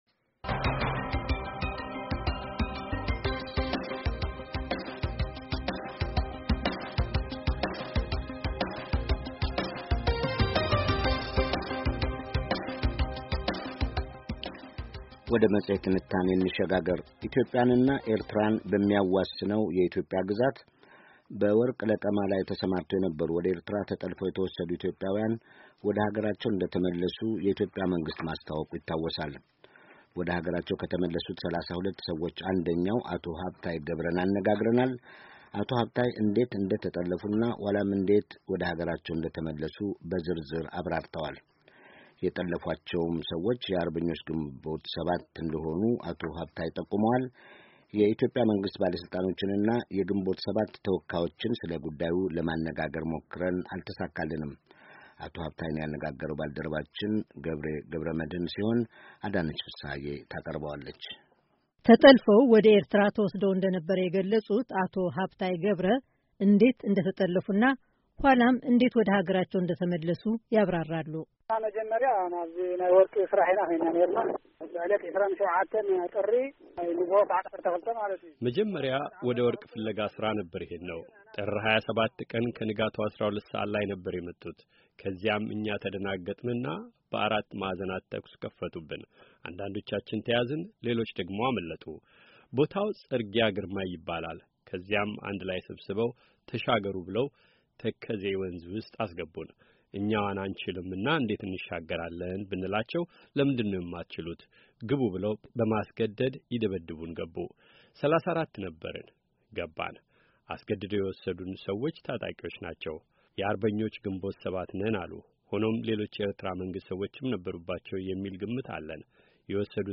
ዜና
ዋሽንግተን ዲሲ —